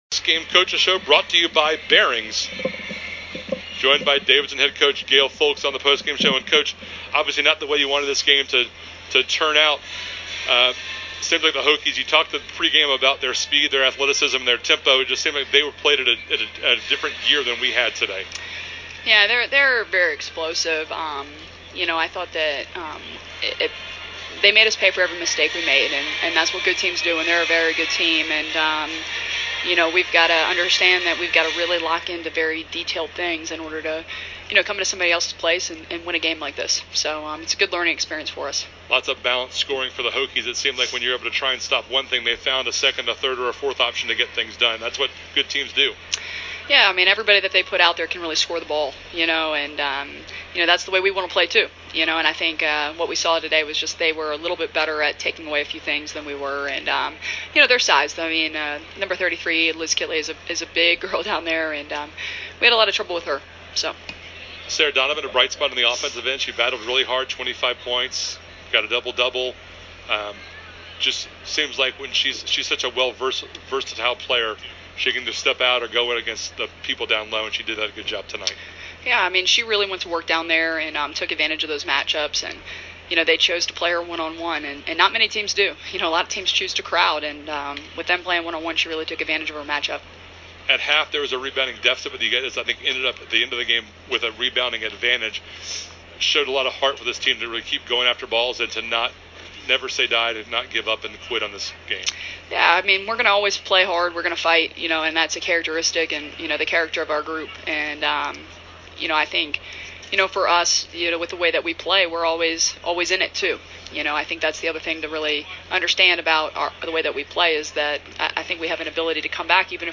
Postgame Interview
Post Game VA Tech WBB.mp3